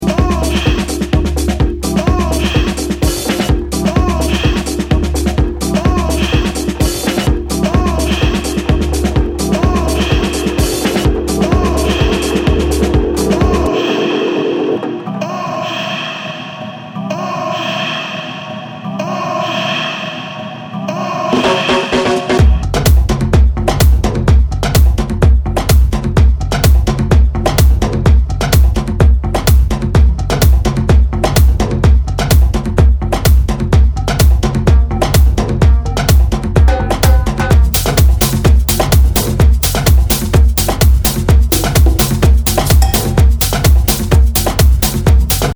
Styl: House, Techno